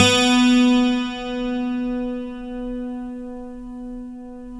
Index of /90_sSampleCDs/Roland L-CD701/GTR_Steel String/GTR_12 String
GTR 12STR 04.wav